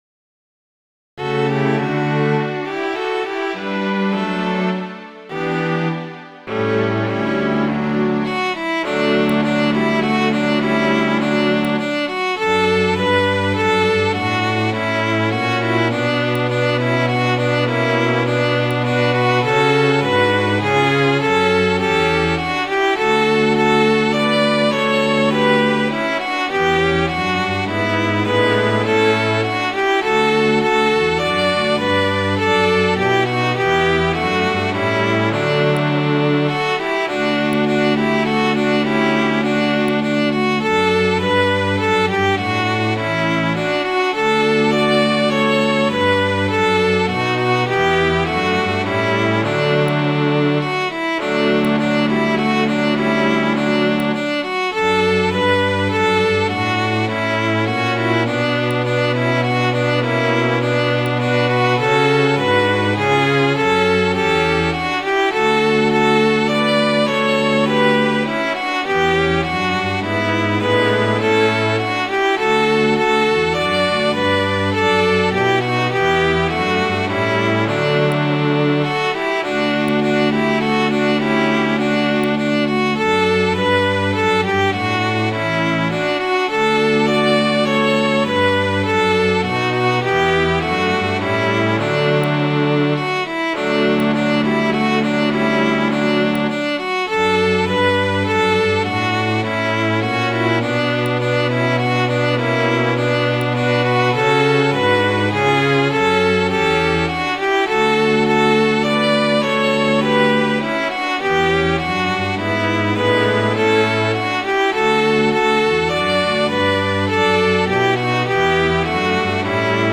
Midi File, Lyrics and Information to Bonny Light Horseman